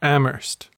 [6] AM-ərst) is a private liberal arts college in Amherst, Massachusetts, United States.
En-us-Amherst.ogg.mp3